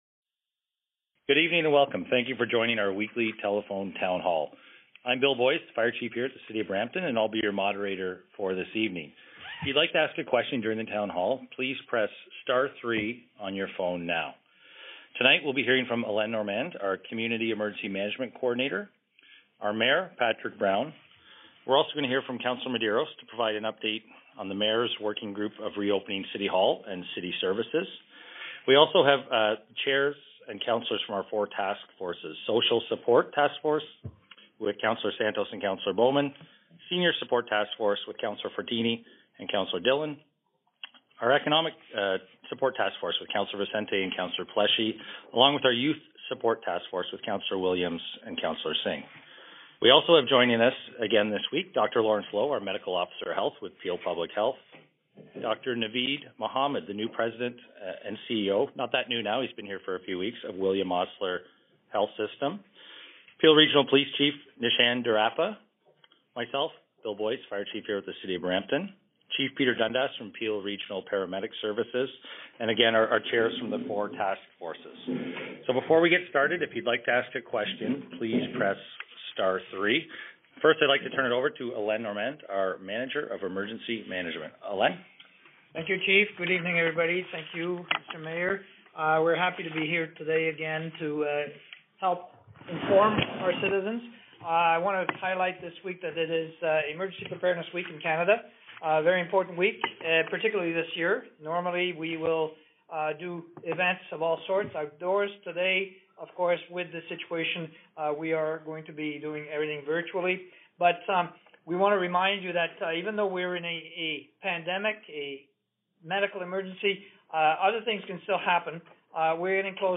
Tele Town Halls
The City of Brampton is hosting Telephone Town Halls related to COVID-19 on an ongoing basis.